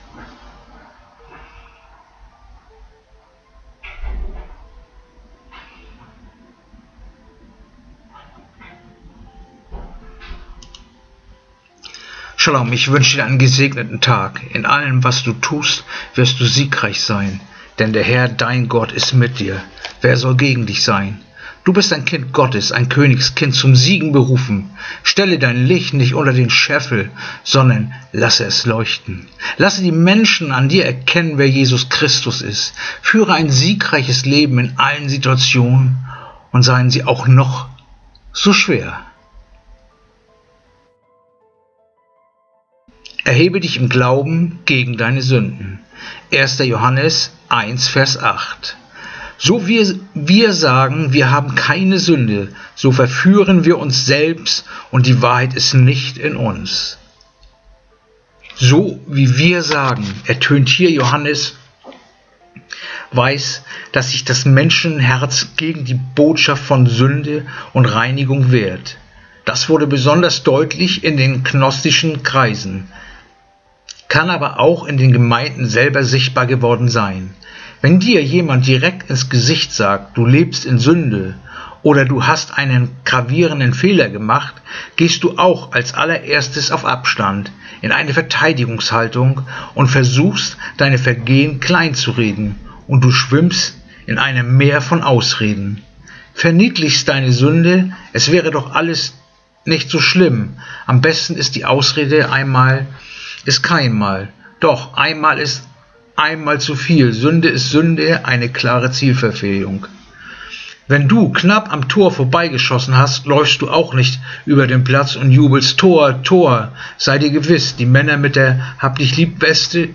Andacht-vom-03-April-1-Johannes-1-8
Andacht-vom-03-April-1-Johannes-1-8.mp3